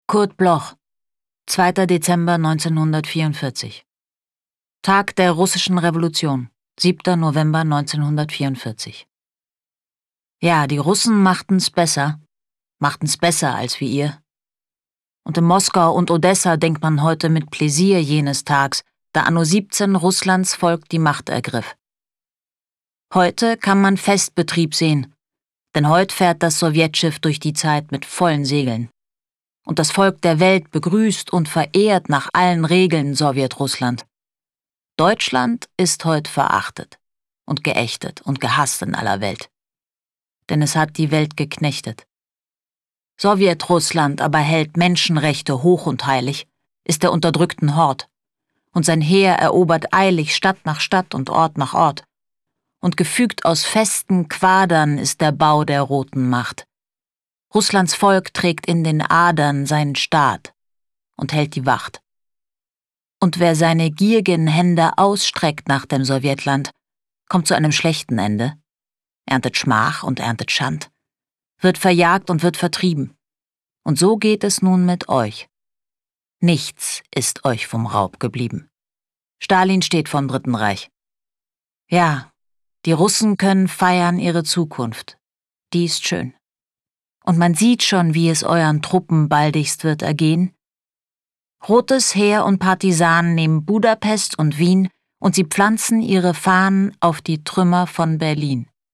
Aufnahme: speak low, Berlin
Julia Koschitz (* 1974) ist eine österreichische Schauspielerin.